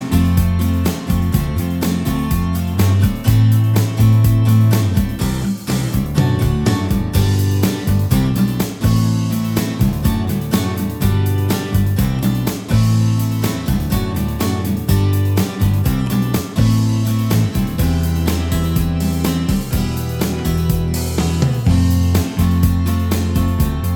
Minus Lead Guitar Indie / Alternative 4:03 Buy £1.50